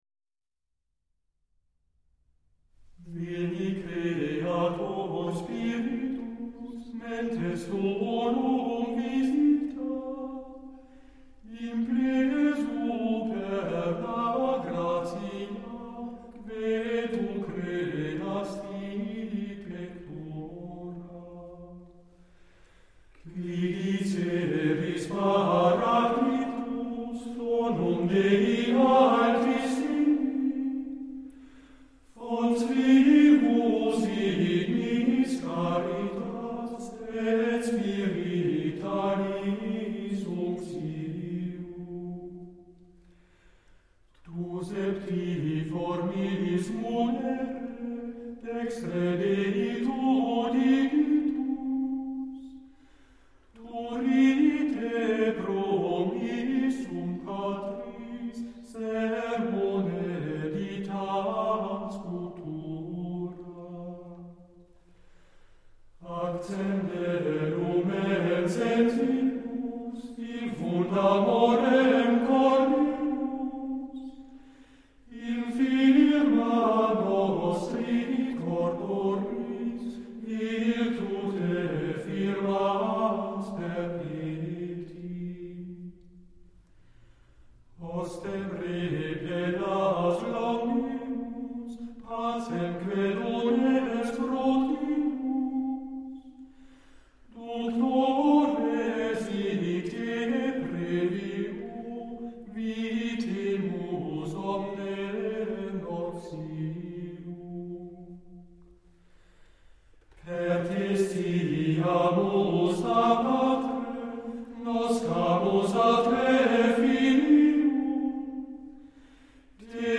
Plainchant
Group: A capella
Hymnus
Playlist includes music from genres: Gregorian chant